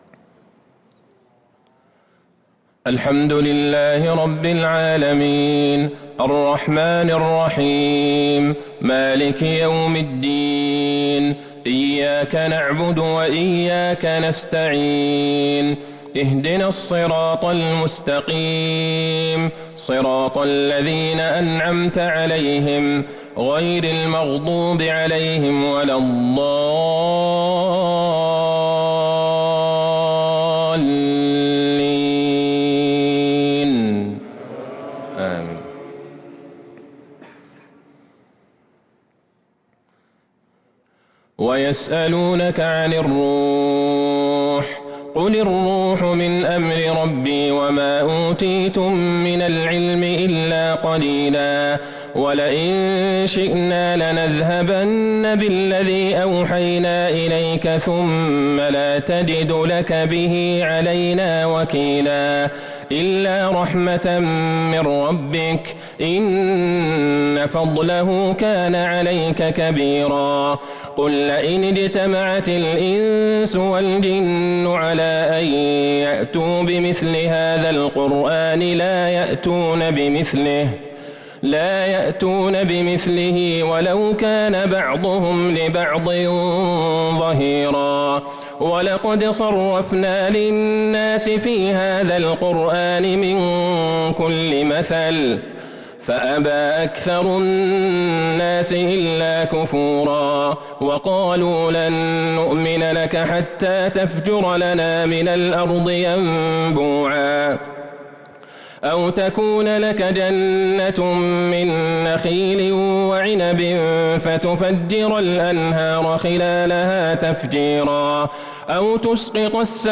صلاة العشاء للقارئ عبدالله البعيجان 27 شعبان 1443 هـ
تِلَاوَات الْحَرَمَيْن .